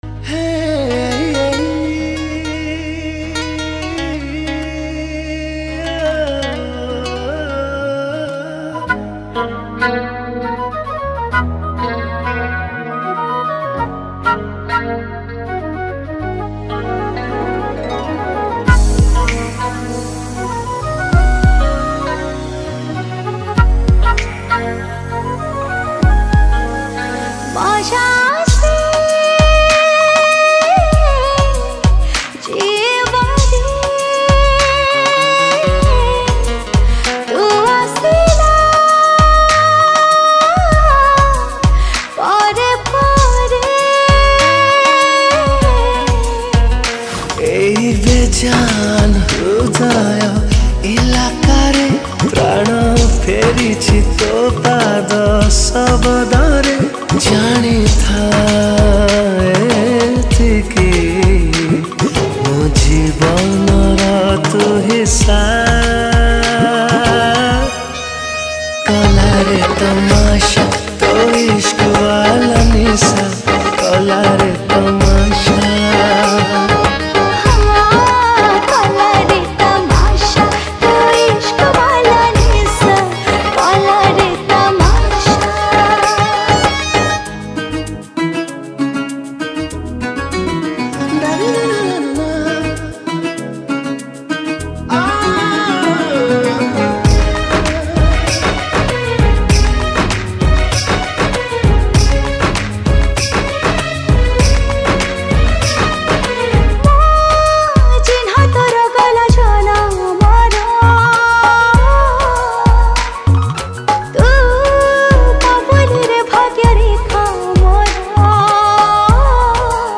Keybord